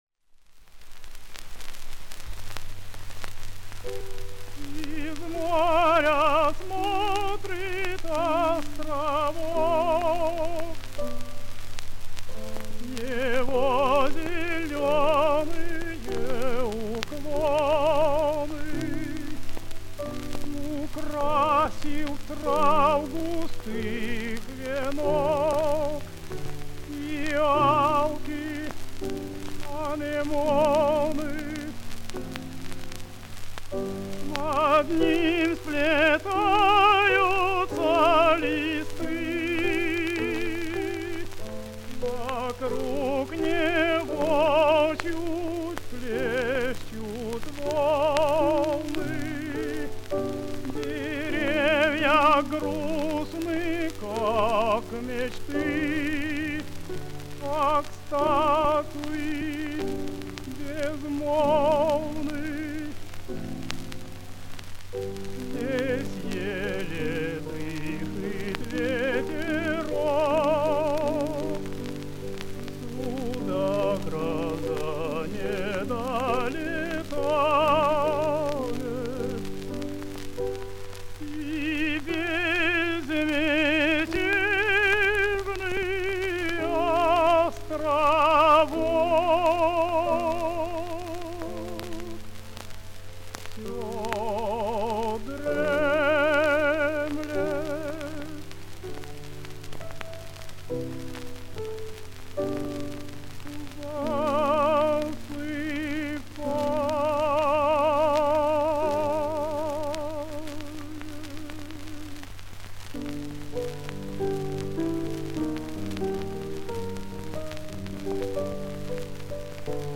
тенор
Романс «Островок».
Партия фортепиано